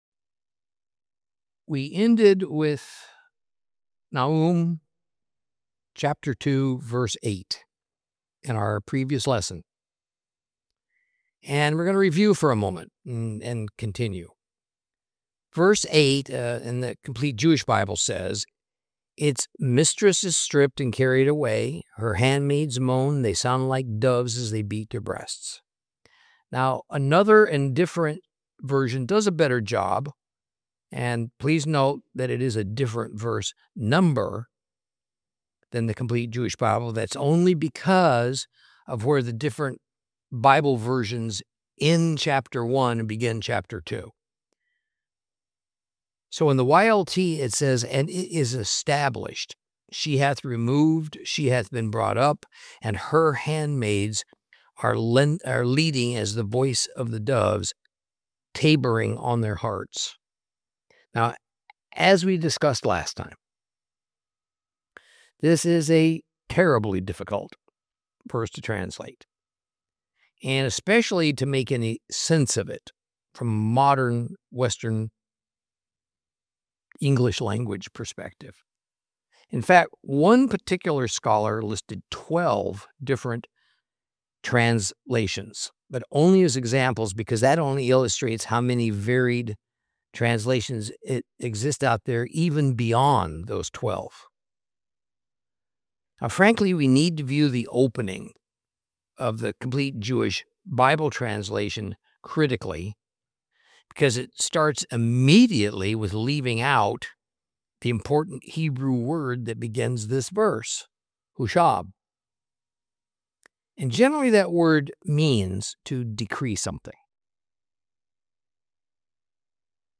Teaching from the book of Nahum, Lesson 6 Chapter 2 conclusion.